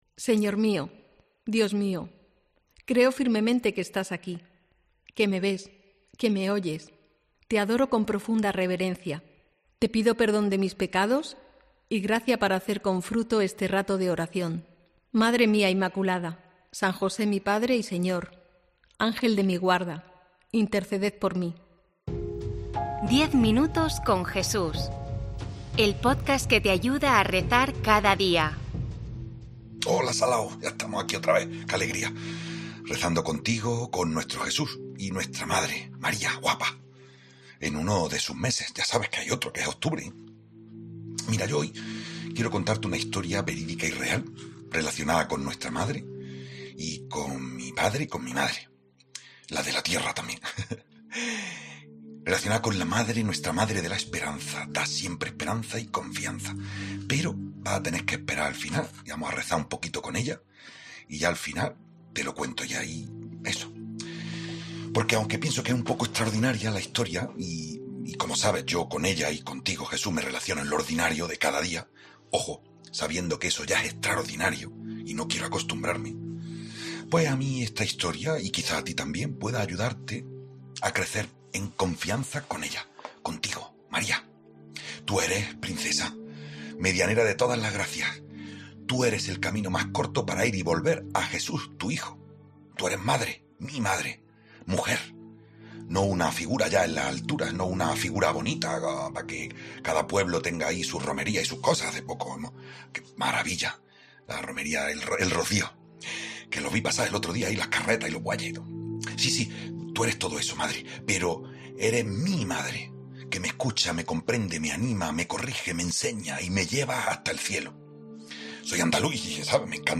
Redacción digital Madrid - Publicado el 20 may 2024, 15:00 1 min lectura Descargar Facebook Twitter Whatsapp Telegram Enviar por email Copiar enlace COPE incorpora a su oferta de podcats '10 minutos con Jesús', una meditación diaria en formato podcast centrada en el Evangelio , en la que se proponen reflexiones y se ofrecen pinceladas sobre la vida de Jesucristo.